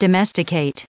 1201_domesticate.ogg